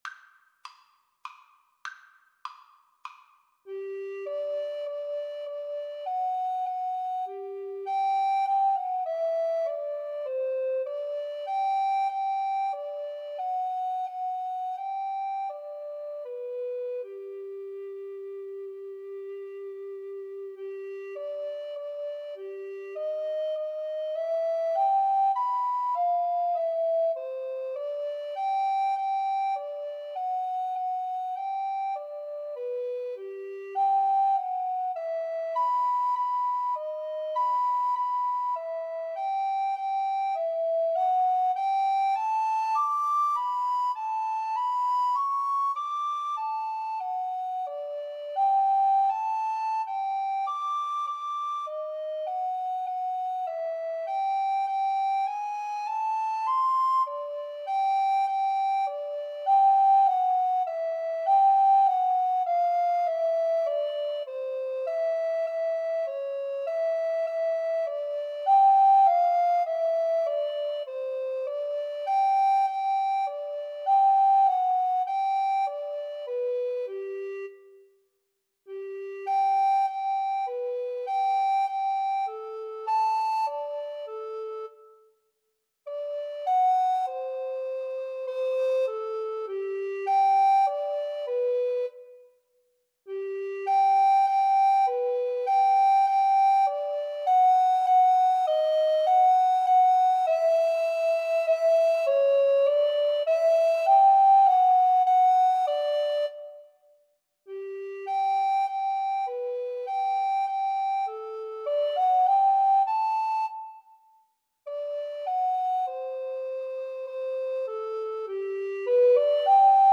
Free Sheet music for Recorder Duet
Soprano RecorderAlto Recorder
G major (Sounding Pitch) (View more G major Music for Recorder Duet )
3/4 (View more 3/4 Music)
Moderato
Traditional (View more Traditional Recorder Duet Music)